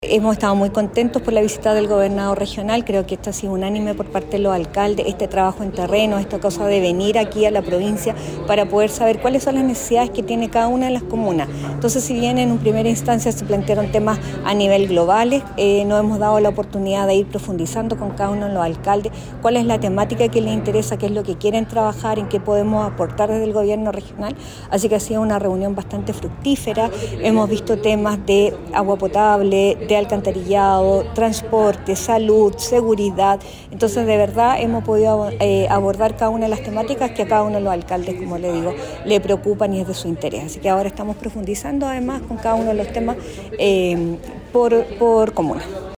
Los Consejeros Regionales de la Provincia de San Felipe, que estuvieron presentes en la reunión, valoraron este primer encuentro de trabajo colaborativo. Así lo manifestó Maricel Martínez(PPD).